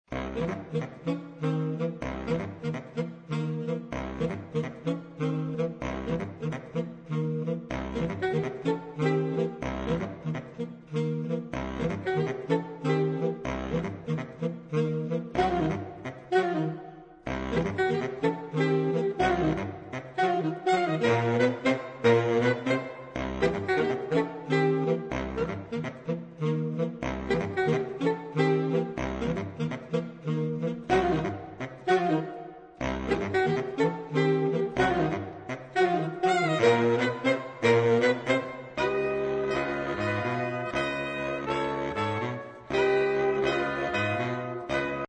saxofon
4 Saxophone (SATBar/AATBar)